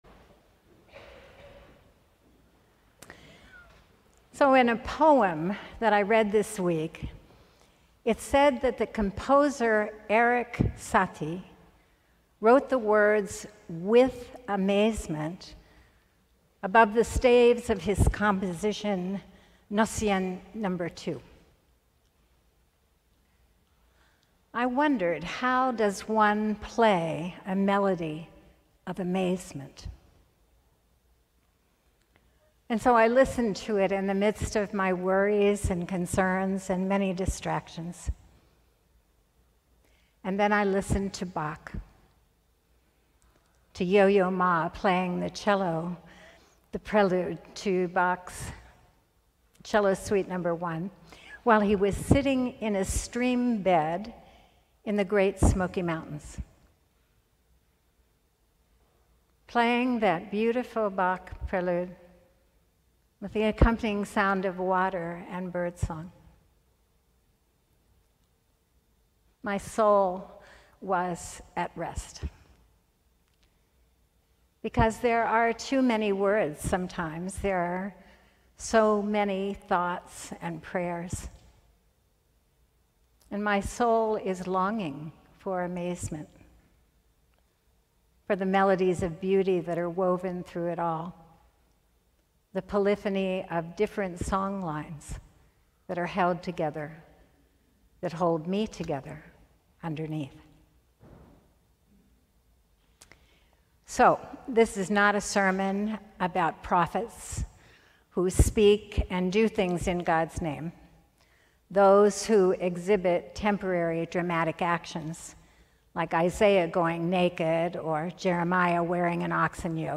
Sermon: The Melody of Amazement - St. John's Cathedral